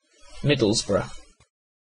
Middlesbrough Football Club (/ˈmɪdəlzbrə/
En-uk-Middlesbrough.ogg.mp3